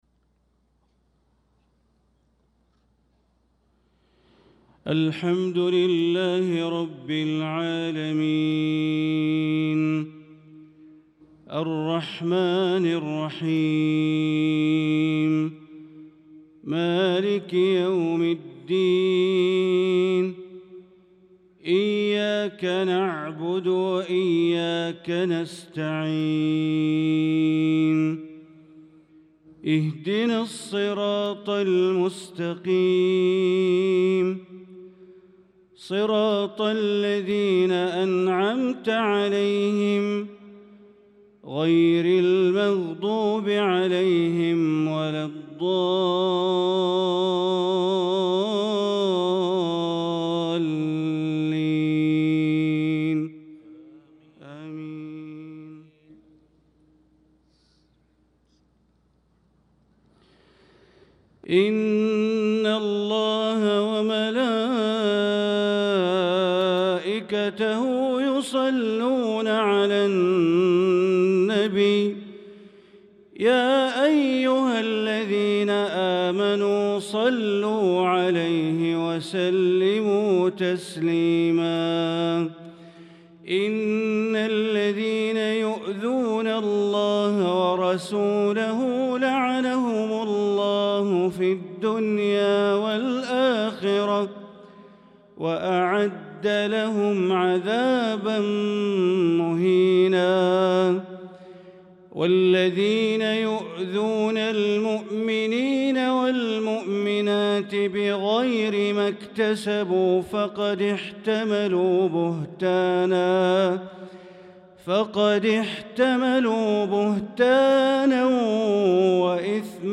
صلاة العشاء للقارئ بندر بليلة 1 ذو القعدة 1445 هـ
تِلَاوَات الْحَرَمَيْن .